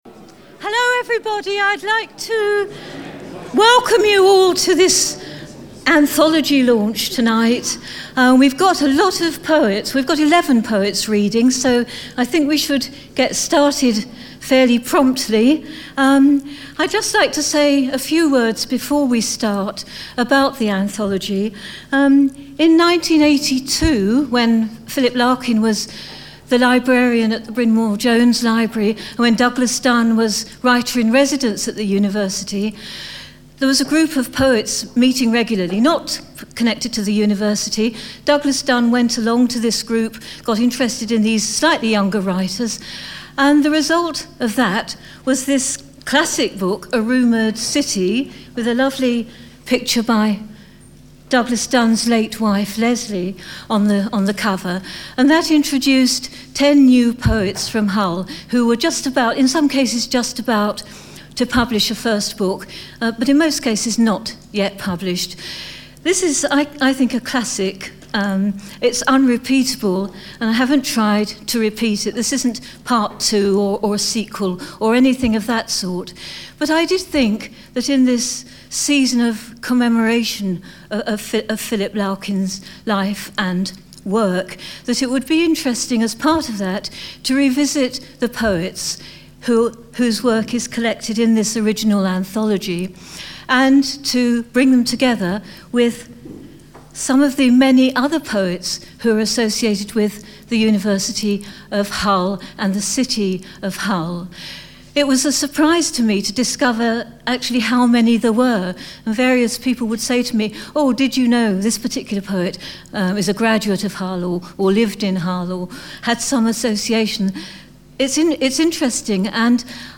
Old City : New Rumours - Book launch event
We celebrate continuity as well as new beginnings in the wonderful variety of poetry represented in Old City: New Rumours. As many as possible of the featured poets will be invited to give short readings this event.